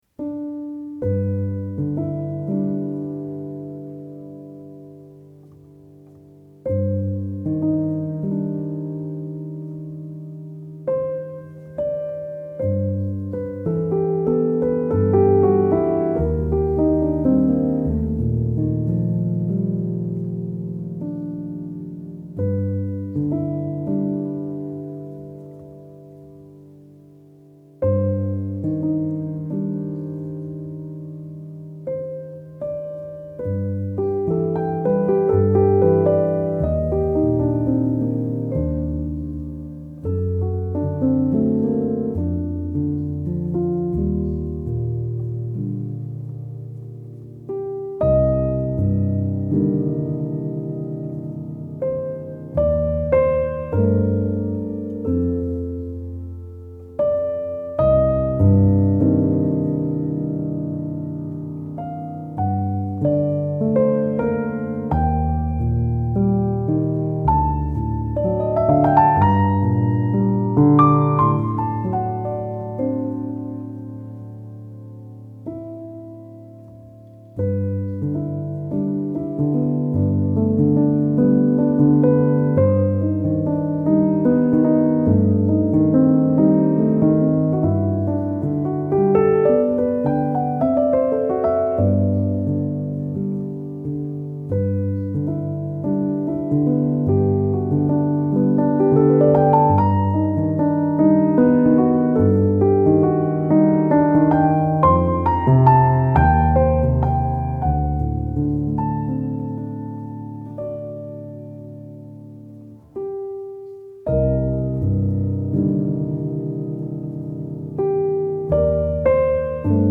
آرامش بخش , الهام‌بخش , پیانو , مدرن کلاسیک , موسیقی بی کلام